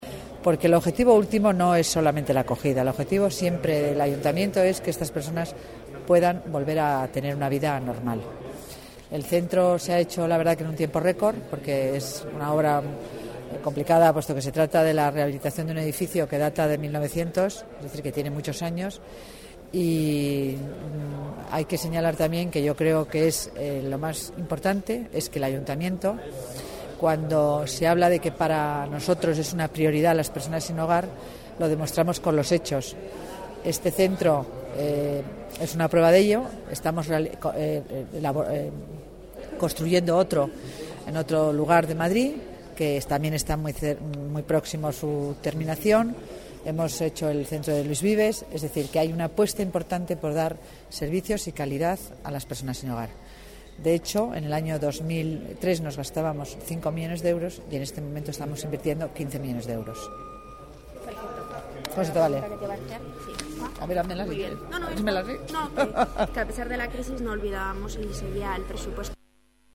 Nueva ventana:Declaraciones de la delegada de Familia y Servicios Sociales, Concepción Dancausa: Centro San Isidro